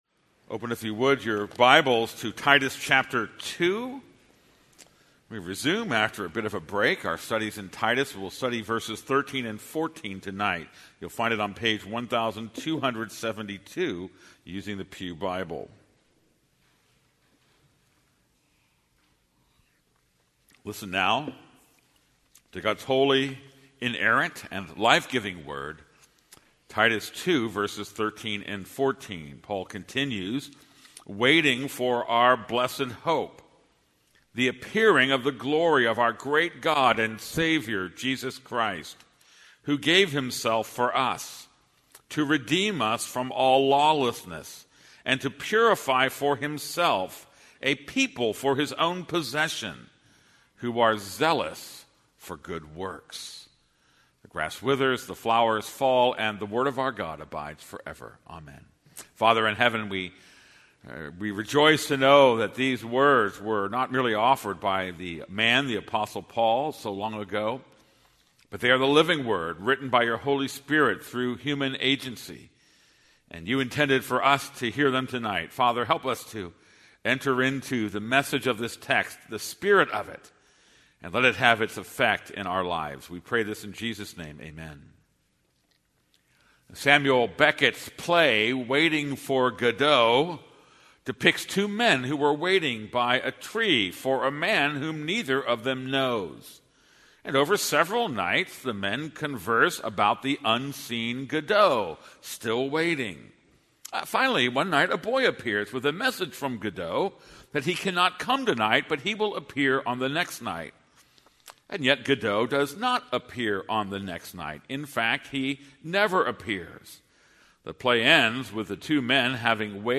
This is a sermon on Titus 2:13-14.